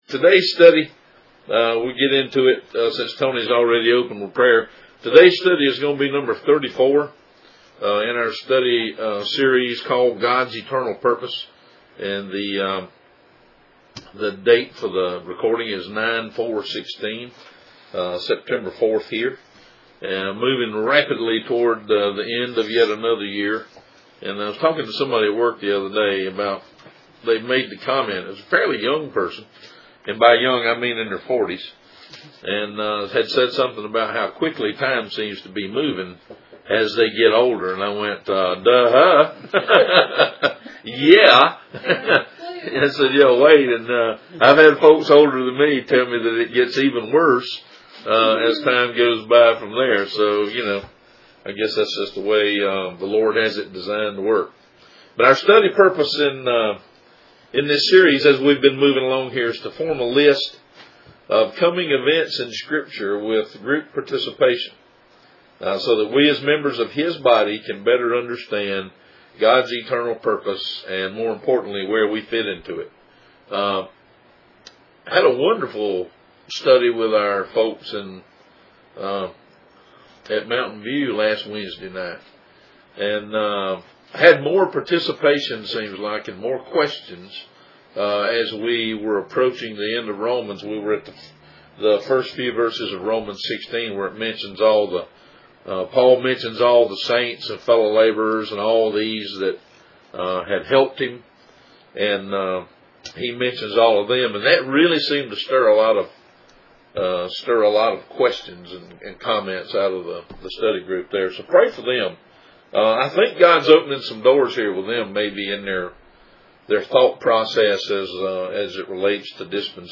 This entry was posted in Bible Study, God's Eternal Purpose.